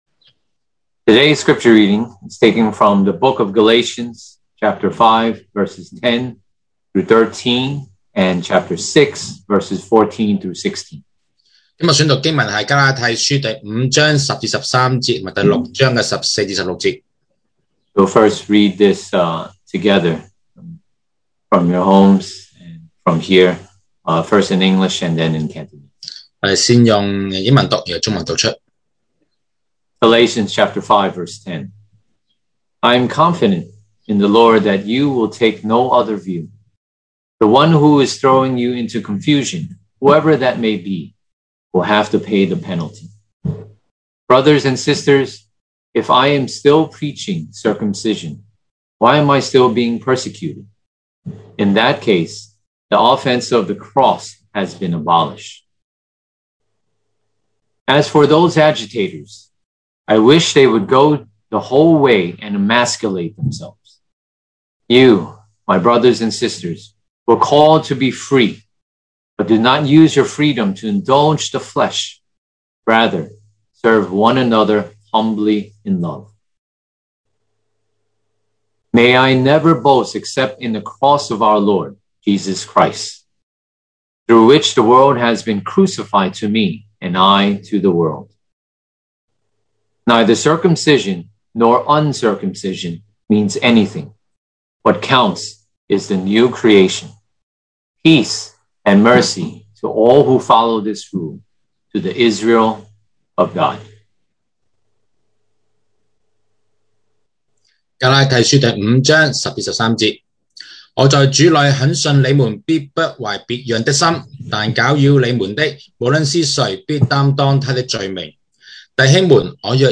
sermon audios
Service Type: Sunday Morning